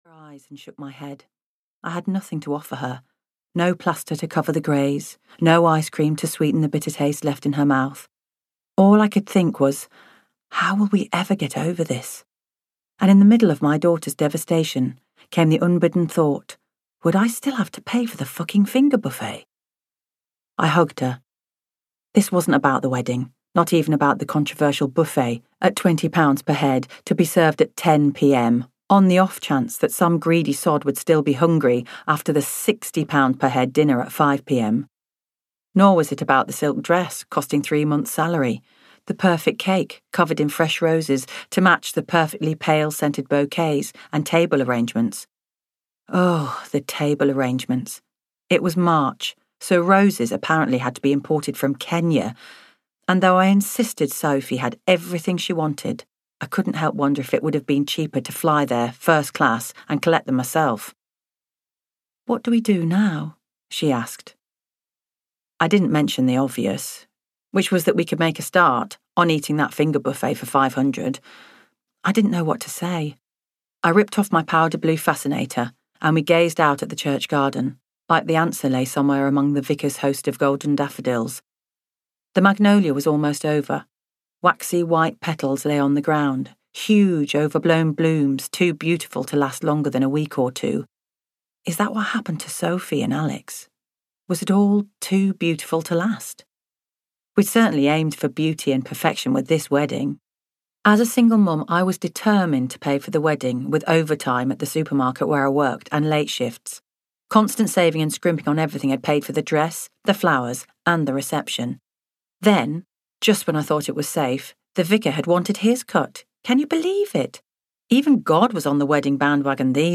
Summer Flings and Dancing Dreams (EN) audiokniha
Ukázka z knihy